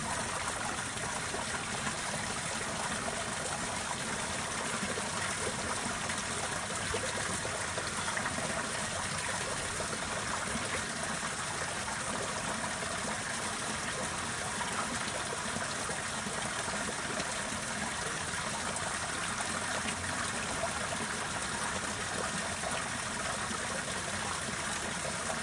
描述：水滴入排水管。 （新西兰）
Tag: 运动 飞溅 滴水